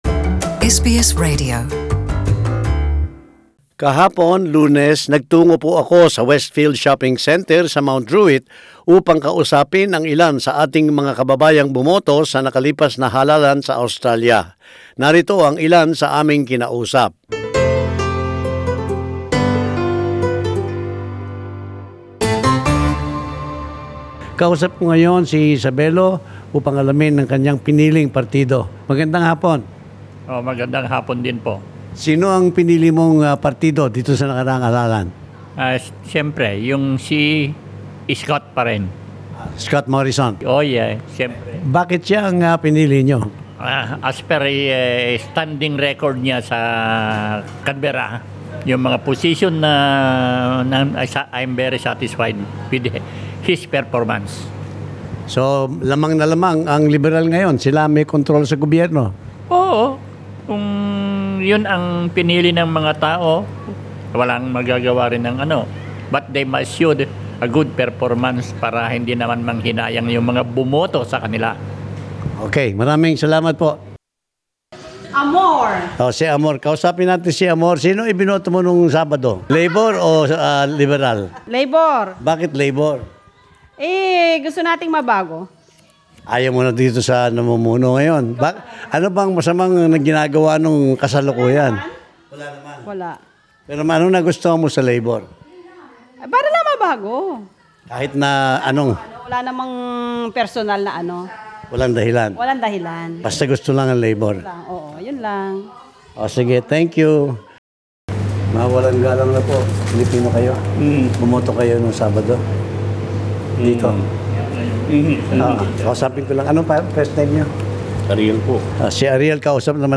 Vox Pop: What can you say about the federal election result?
We asked Sydney-based Filipino migrants about their opinion on the result of the 2019 federal election where Coalition is re-elected for another term.